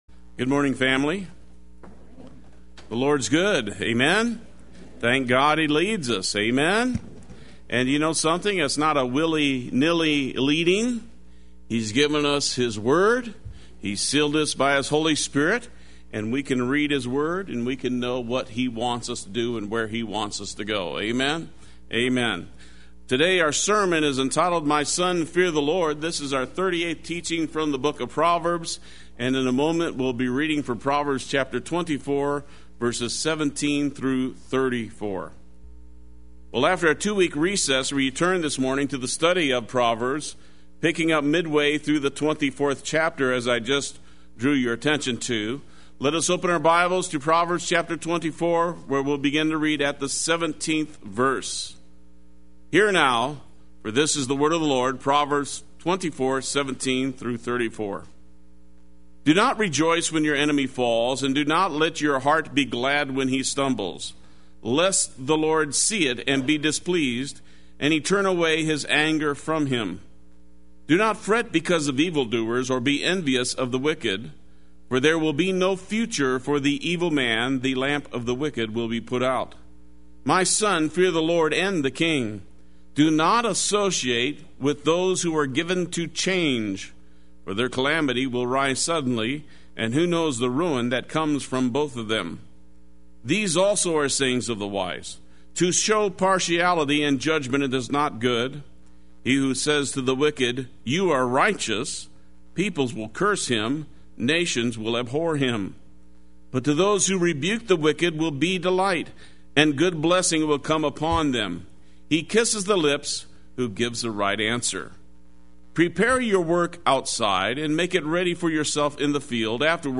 Play Sermon Get HCF Teaching Automatically.
Fear the Lord” Sunday Worship